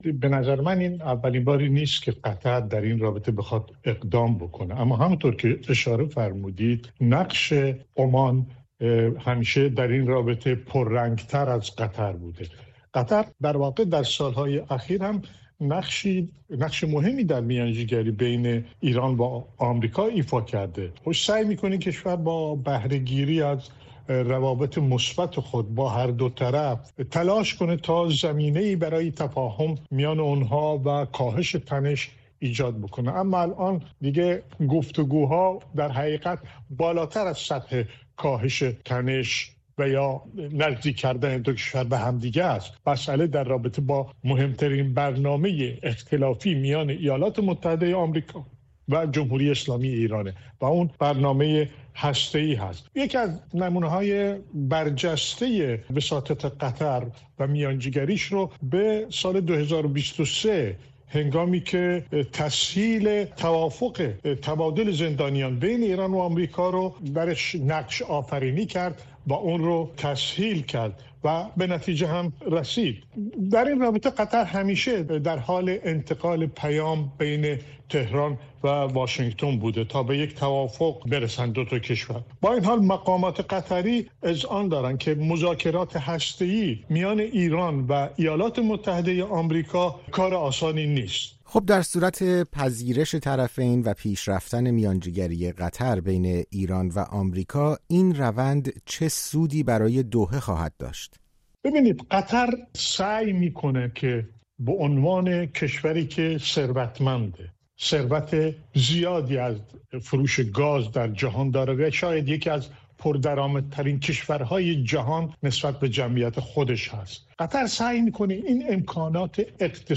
دلایل پیشنهاد قطر برای میانجی‌گری بین ایران و آمریکا؛ گفت‌وگو با یک کارشناس